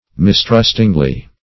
mistrustingly - definition of mistrustingly - synonyms, pronunciation, spelling from Free Dictionary Search Result for " mistrustingly" : The Collaborative International Dictionary of English v.0.48: Mistrustingly \Mis*trust"ing*ly\, adv. With distrust or suspicion.